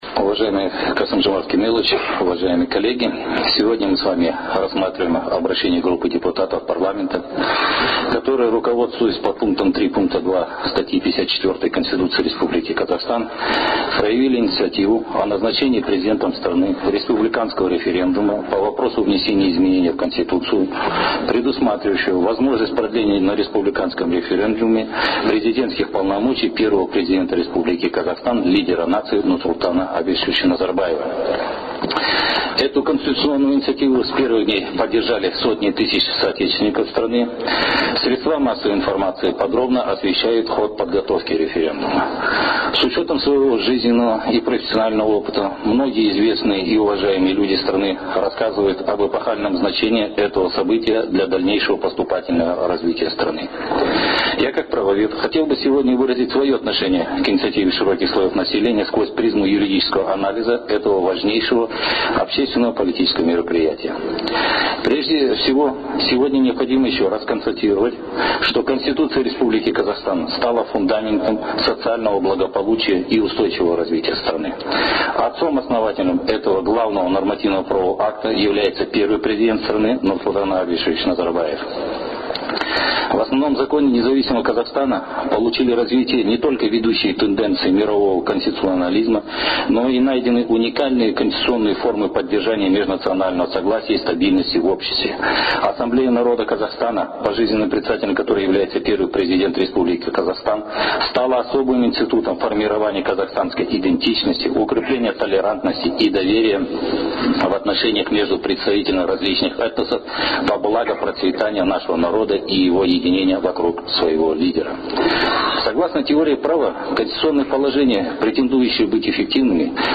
Выступление председателя комитета по законодательству и правовым вопросам Берика Имашева на пленарном заседании Сената Парламента.